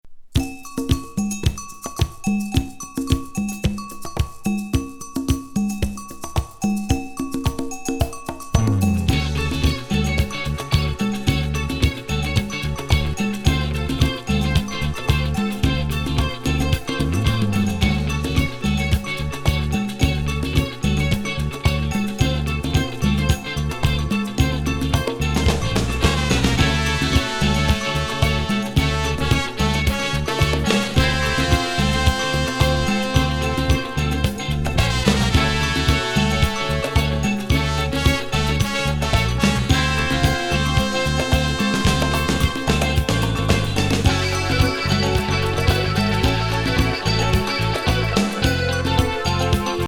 本格派ディスコ・イントロ
スタカン的モダン・ブルーアイドソウル